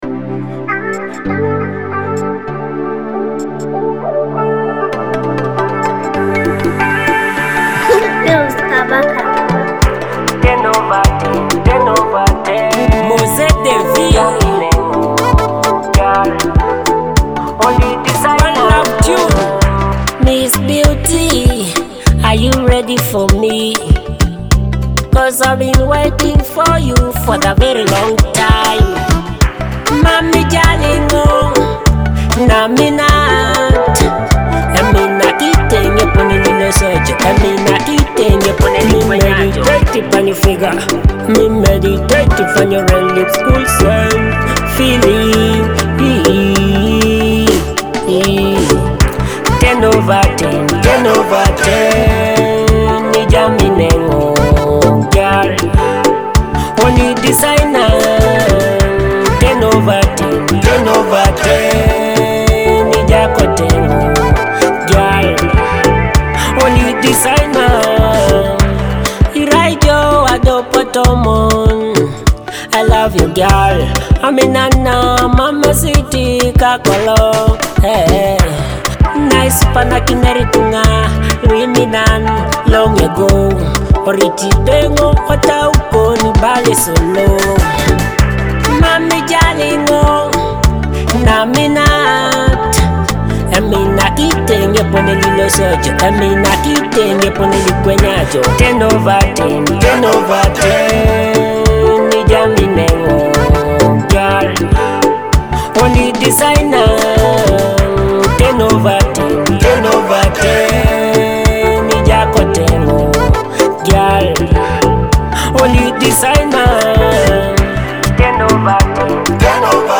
an electrifying dancehall track with Afrobeat influences.